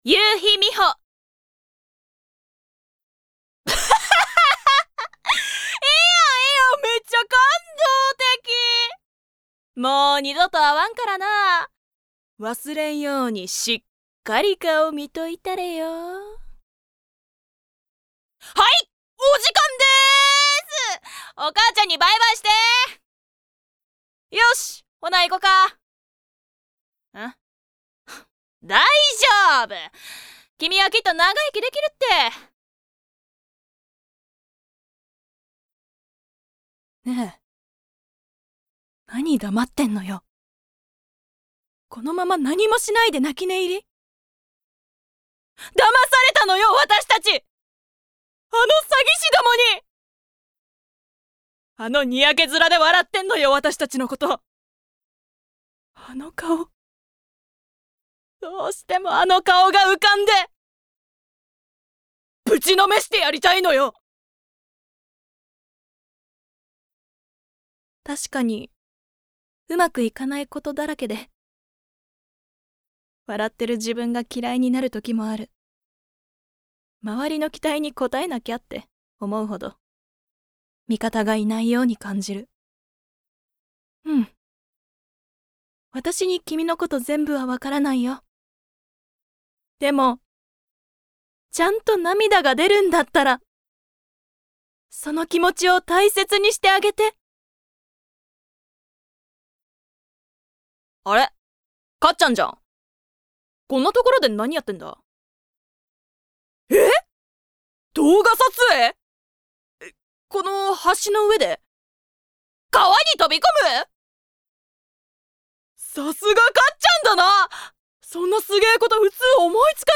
誕生日： 6月6日 血液型： A型 身 長： 172cm 出身地： 兵庫県 趣味・特技： 映画・音楽鑑賞・観劇・美術館・ランニング 資格： 中級バイオ技術者・日本漢字能力検定準二級・普通自動車第一種運転免許 方言： 関西弁
VOICE SAMPLE